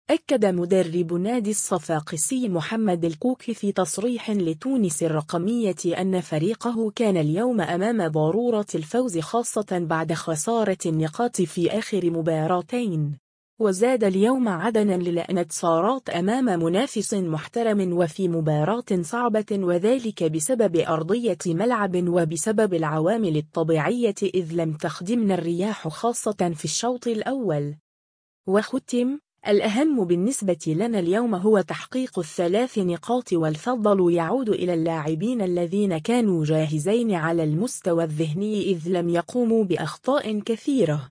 أكّد مدرّب النادي الصفاقسي محمّد الكوكي في تصريح لتونس الرقمية أنّ فريقه كان اليوم أمام ضرورة الفوز خاصة بعد خسارة النقاط في آخر مباراتين.